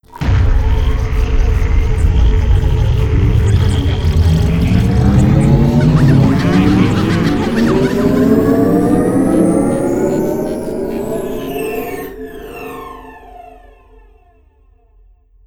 assets/pc/nzp/sounds/machines/power.wav at 6d305bdbde965e83d143ab8cd4841a6c7b68160c
power.wav